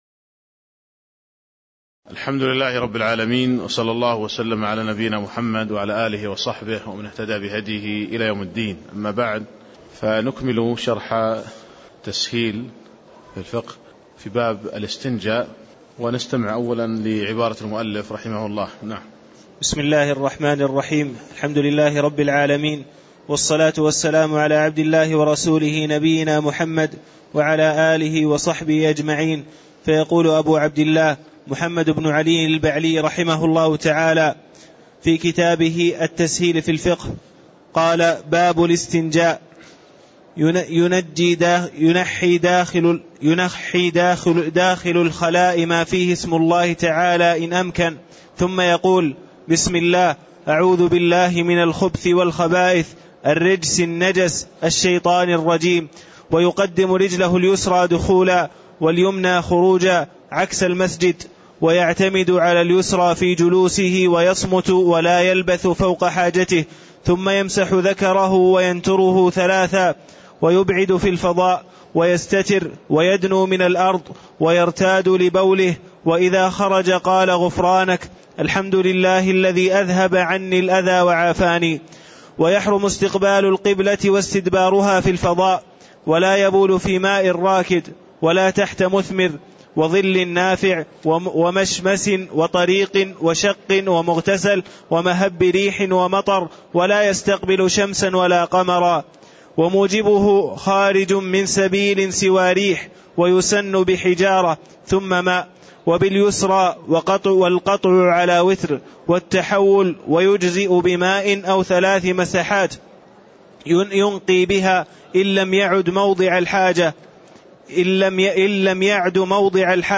تاريخ النشر ١٣ شوال ١٤٣٦ هـ المكان: المسجد النبوي الشيخ